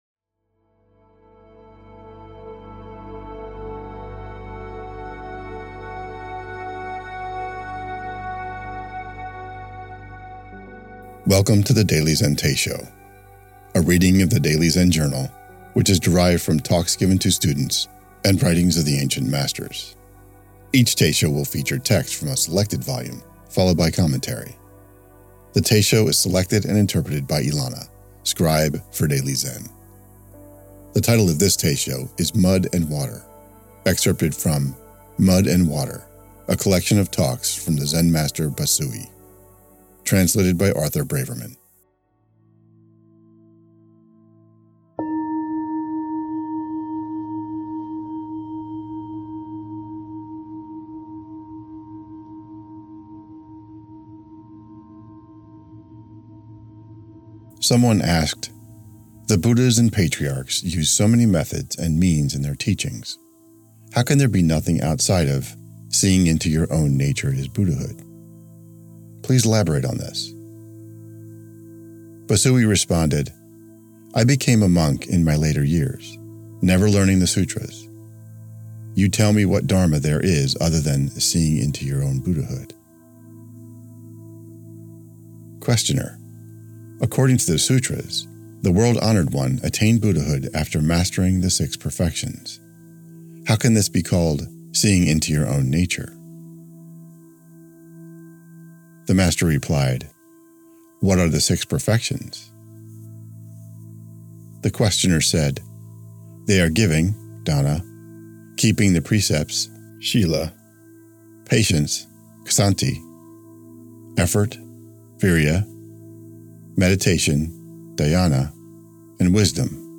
Teisho